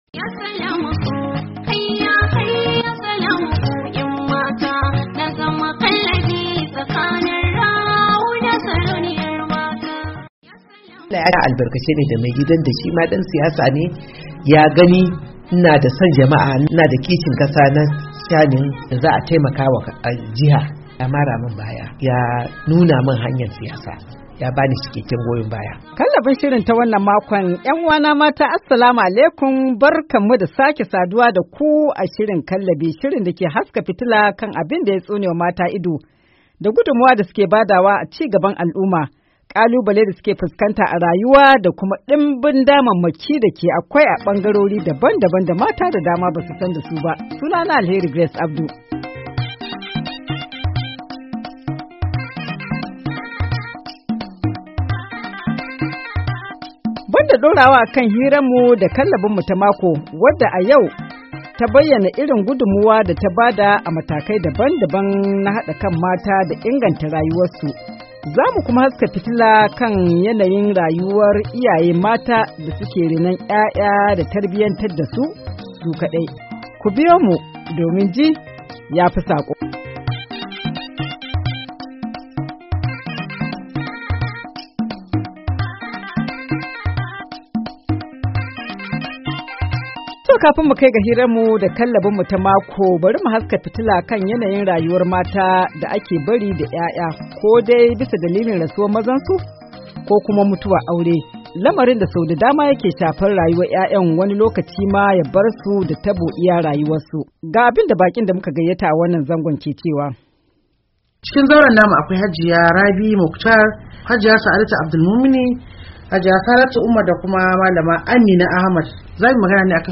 Banda cigaban hira da tsohuwar Ministar mata a Najeriya ta baya bayan nan, Pauline Tallen, shirin kallabi ya kuma haska fitila kan masababin barwa mata nawayar kula da ‘ya’yansu ko da kuwa suna tare da iyayen maza.